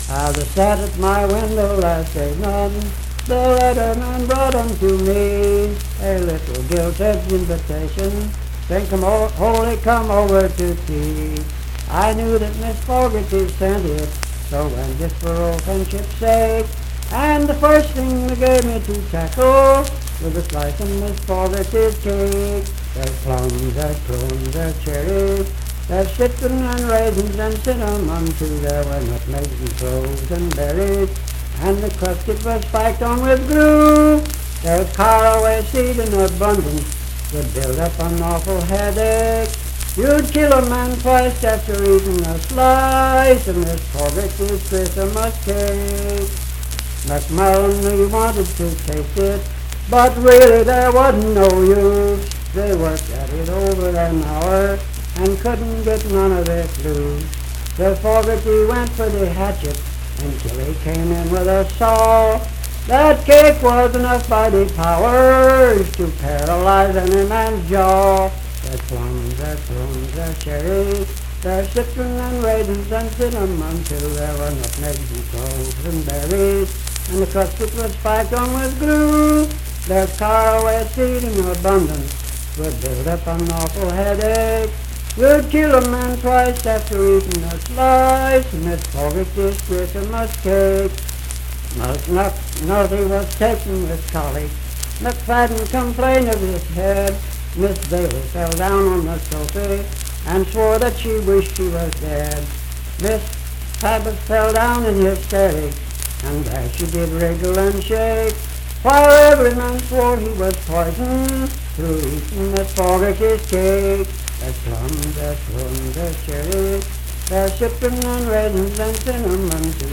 Unaccompanied vocal performance
Voice (sung)